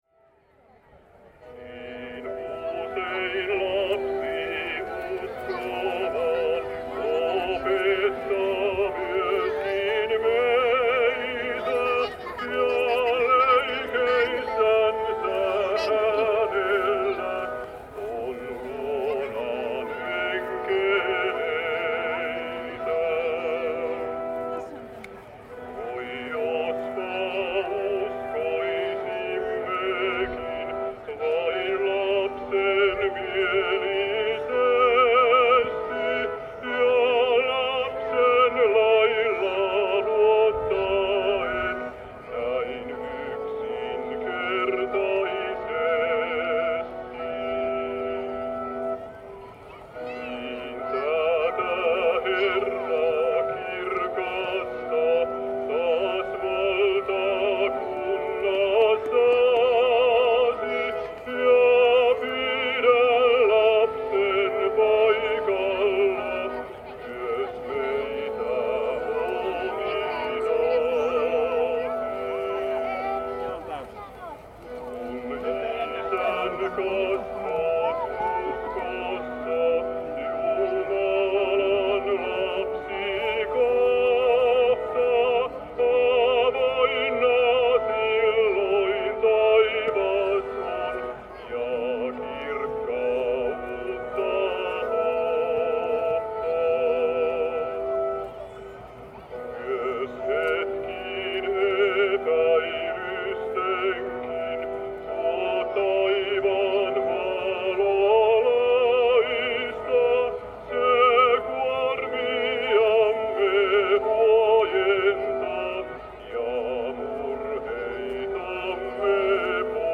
Summer services (Suviseurat in Finnish) is the annual meeting of Conservative Laestadian movement.
Summer services is the biggest spiritual meeting in Finland and one of the biggest summer festivals gathering somewhat 80000 people every year.
• Soundscape
• gathering
• hymn